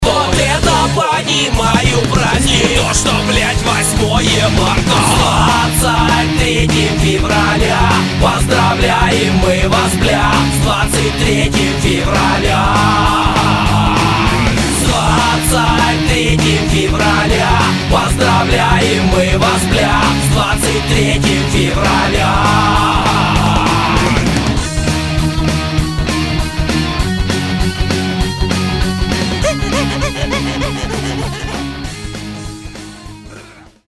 • Качество: 192, Stereo
В мелодии присутствует ненормативная лексика.